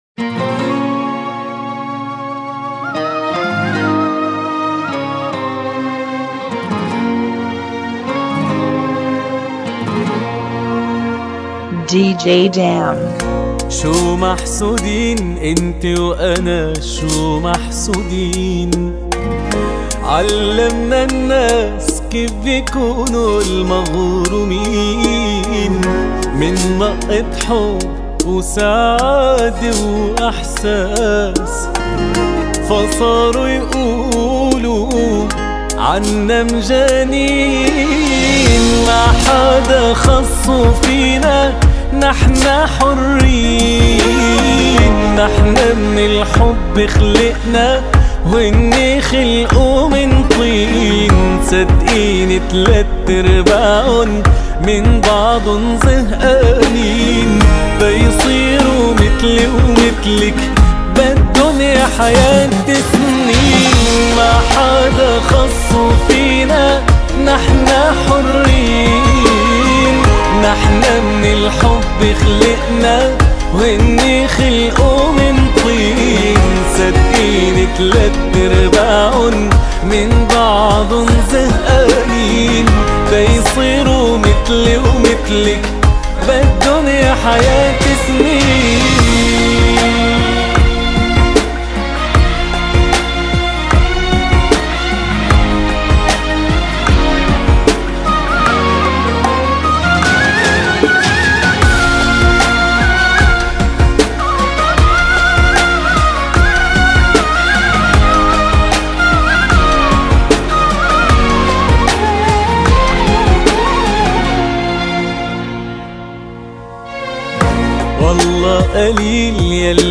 151 BPM
Genre: Bachata Remix